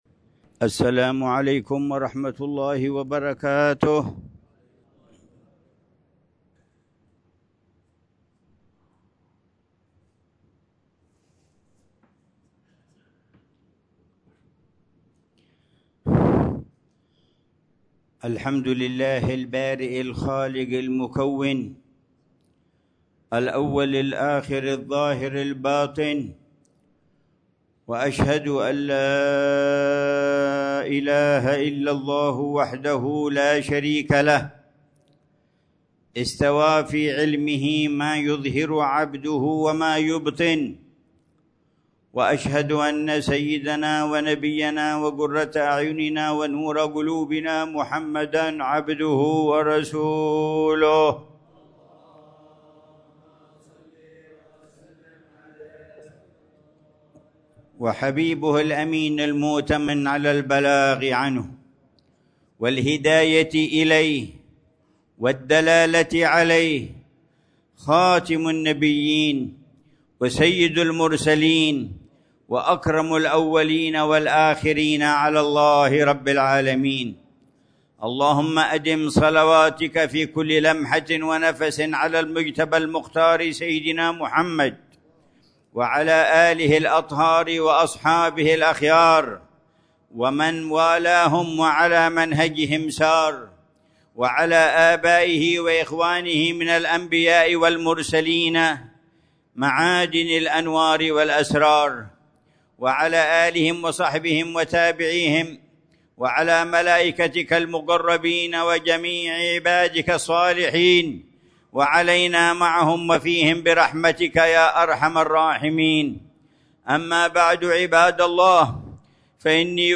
خطبة الجمعة
في جامع المشهد، بوادي حضرموت